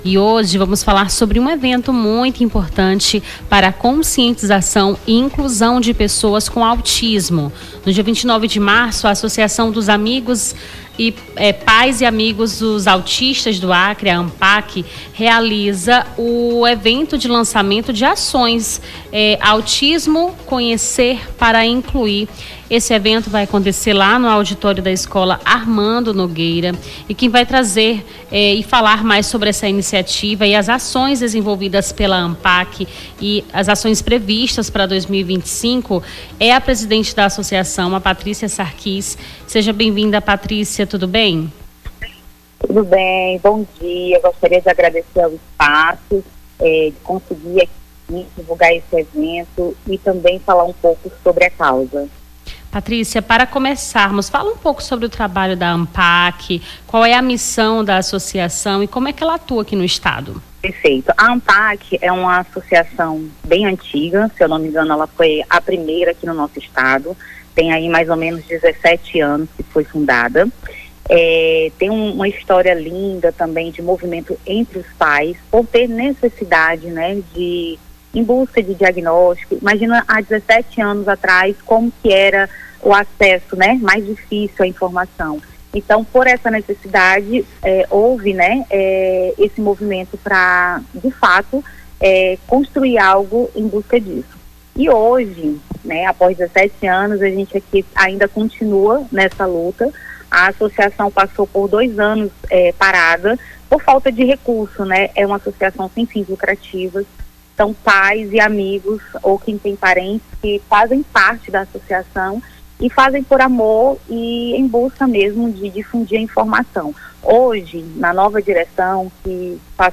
Baixar Esta Trilha Nome do Artista - CENSURA - ENTREVISTA AMPAC AUTISMO (25-03-25).mp3 Foto: Divulgação/MPAC Facebook Twitter LinkedIn Whatsapp Whatsapp Tópicos Rio Branco Acre Ampac MPAC Autismo projeto Programação